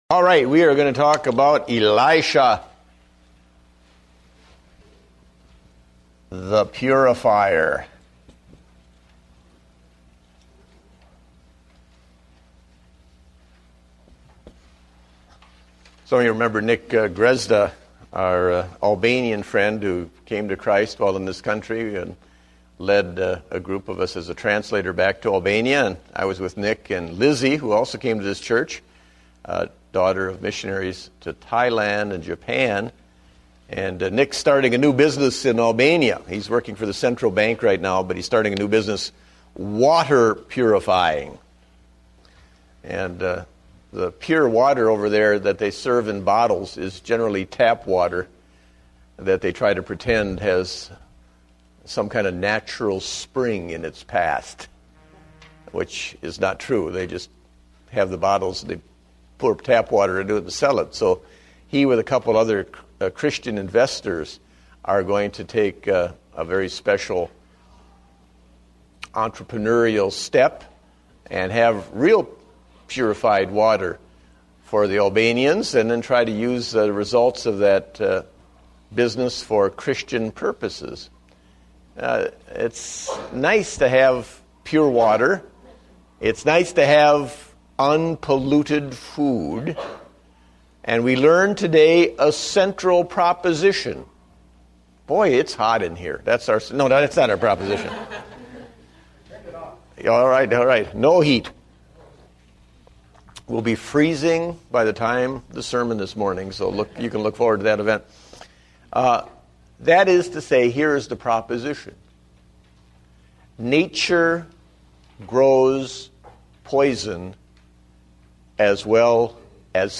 Date: November 29, 2009 (Adult Sunday School)